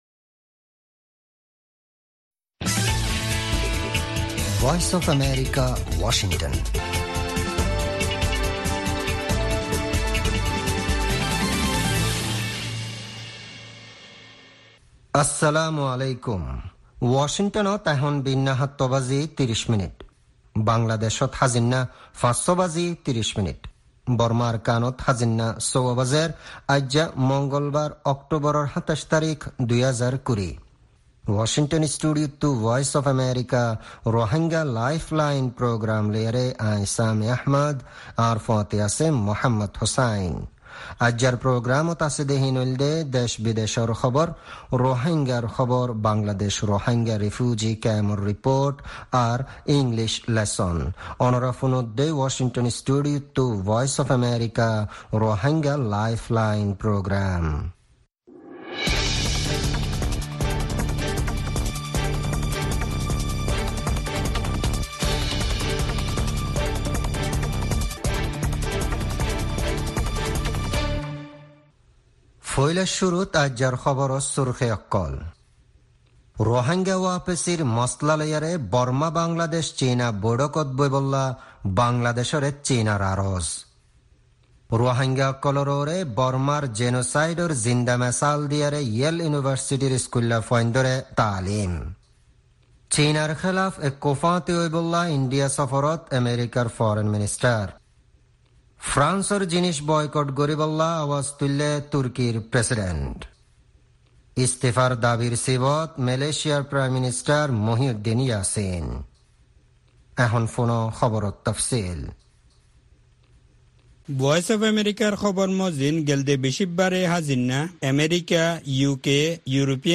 Rohingya Broadcast
News Headlines